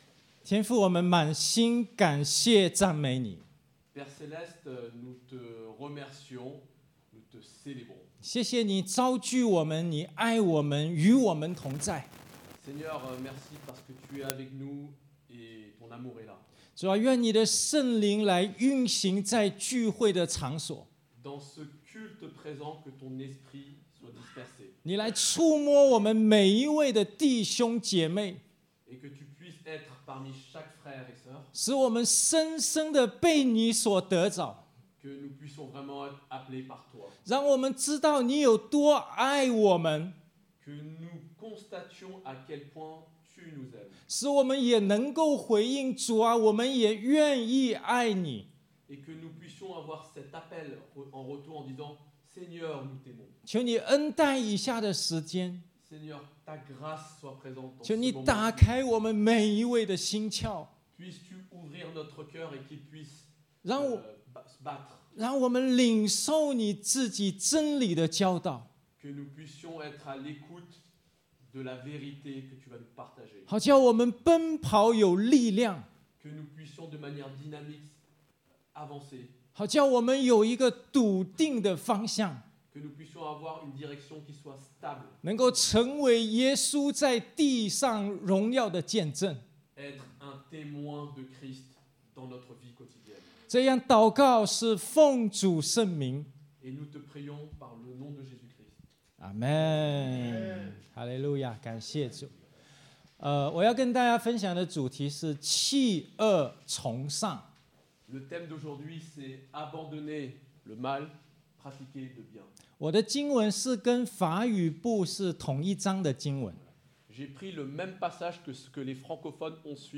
(chinois traduit en français)